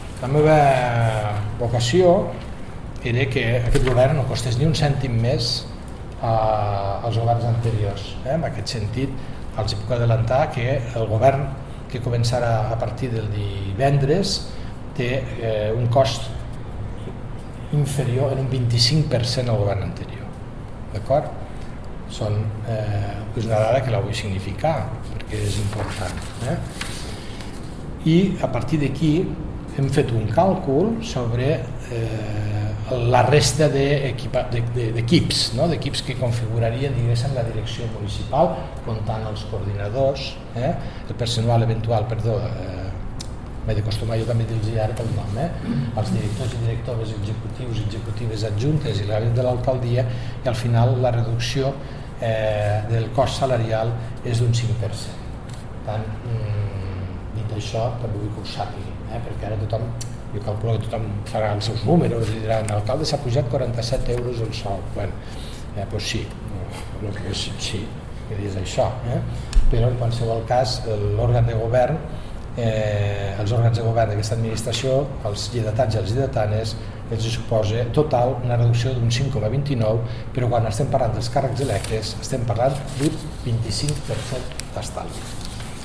Proposta d'acord de cartipàs municipal (578.8 KB) ©Ajuntament de Lleida Tall de veu de l'alcalde, Fèlix Larrosa, sobre el nou cartipàs municipals que divendres se sotmetrà a consideració del Ple (855.5 KB)